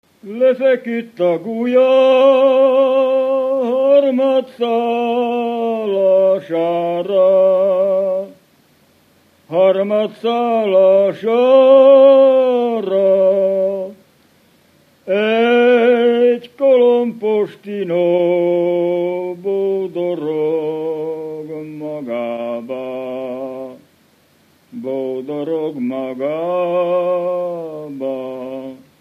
Felföld - Gömör és Kishont vm. - Hárskút
ének
Stílus: 1.1. Ereszkedő kvintváltó pentaton dallamok
Kadencia: 8 5 (5) 4 1 1